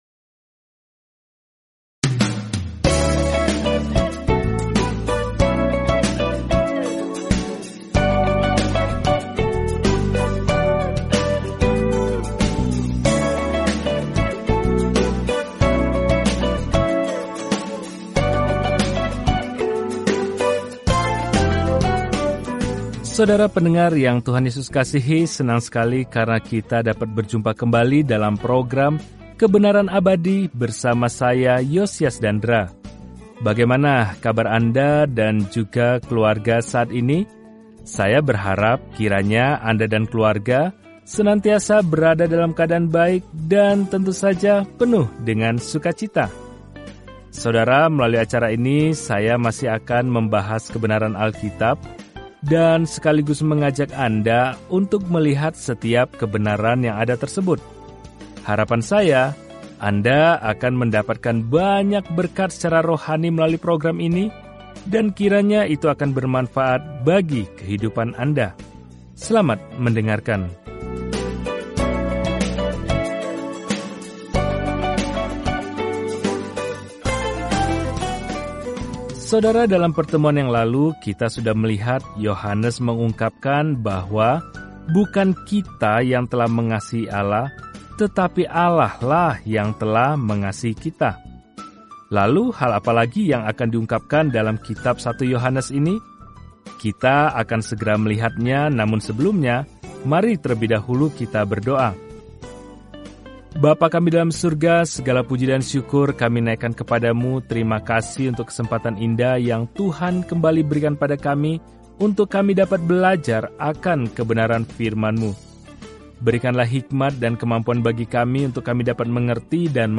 Firman Tuhan, Alkitab 1 Yohanes 4:11-15 Hari 20 Mulai Rencana ini Hari 22 Tentang Rencana ini Tidak ada jalan tengah dalam surat pertama Yohanes ini – kita memilih terang atau gelap, kebenaran daripada kebohongan, cinta atau benci; kita menganut salah satunya, sama seperti kita percaya atau menyangkal Tuhan Yesus Kristus. Telusuri 1 Yohanes setiap hari sambil mendengarkan pelajaran audio dan membaca ayat-ayat tertentu dari firman Tuhan.